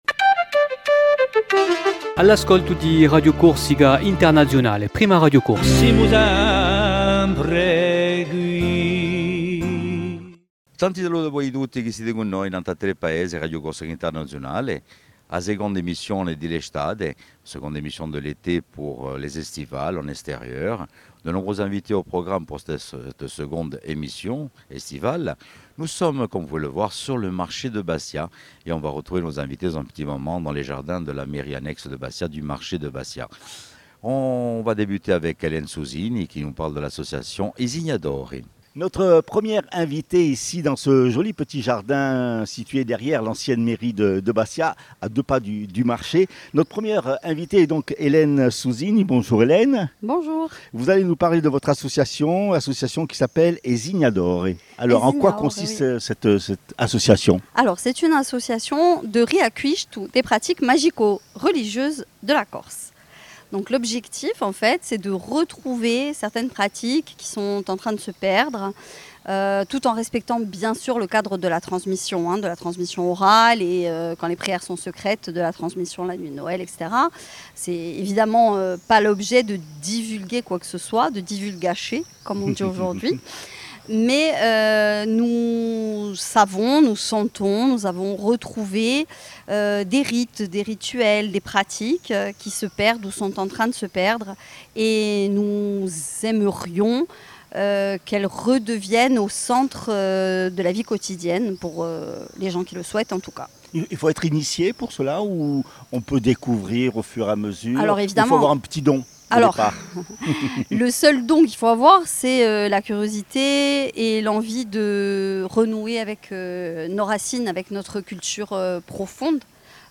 ESTIVALE DE RCI AU MARCHE DE BASTIA